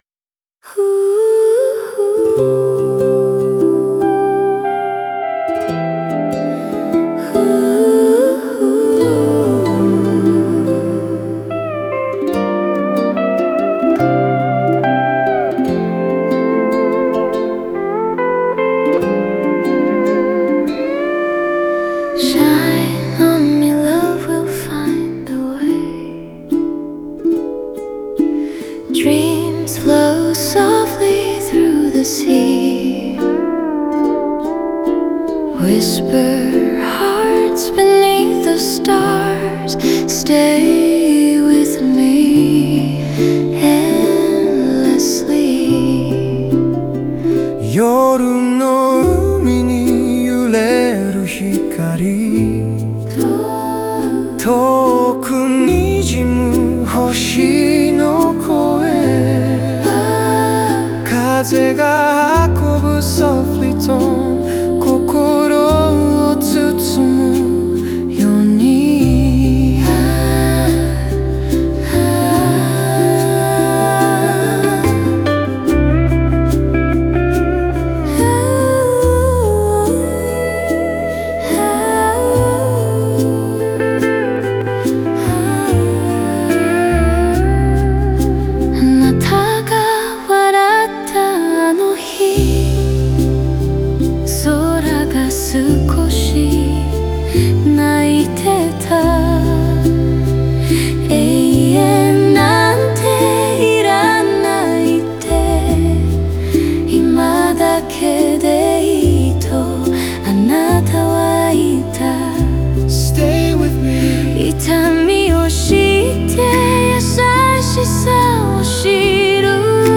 英語のコーラスが波のように寄せ、心の奥に残る「愛は消えない」という余韻を優しく響かせる。